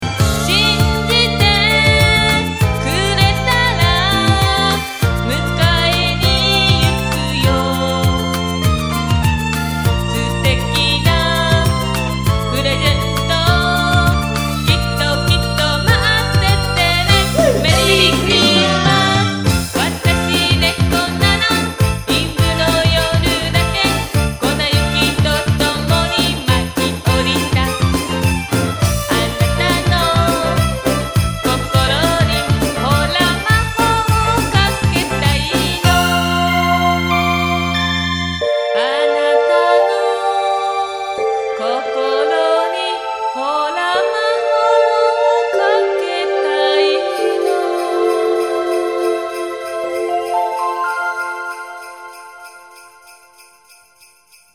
おしり　 　　ＭＰ３ファィル・・・1.00ＭＢ　急に音が出ます！音量注意！！